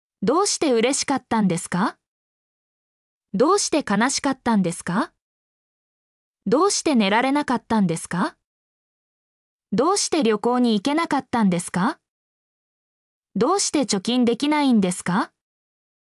mp3-output-ttsfreedotcom-6_CpoO9nLc.mp3